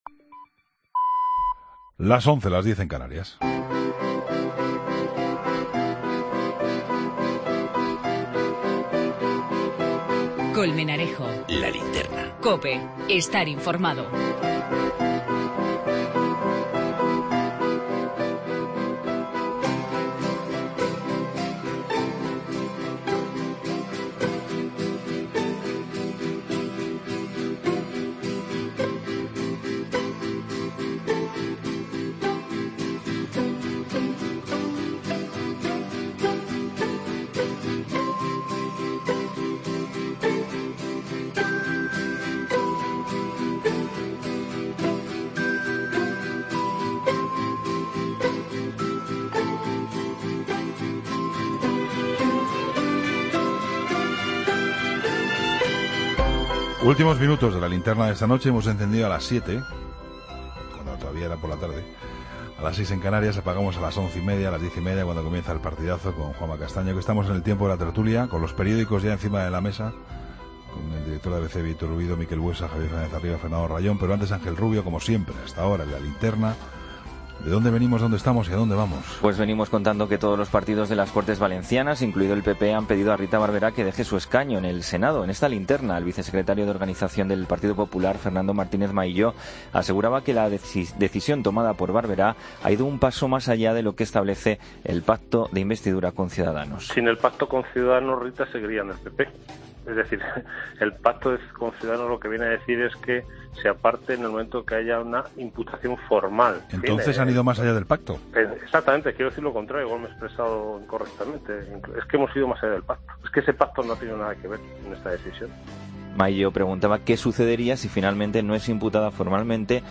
Tertulia II, jueves 15 de septiembre de 2016